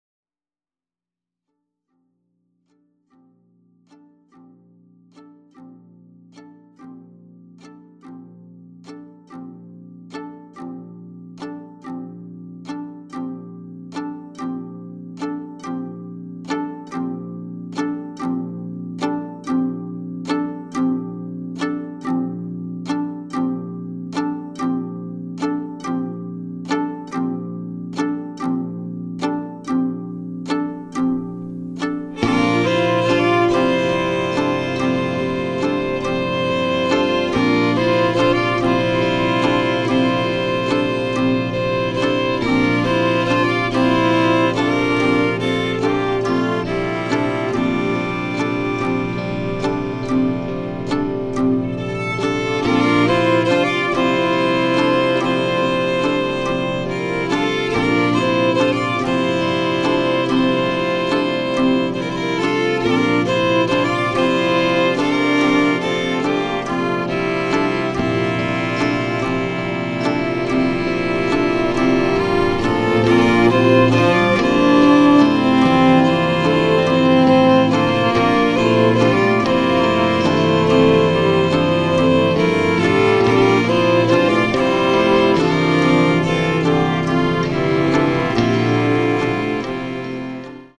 results in an Mbira-like effect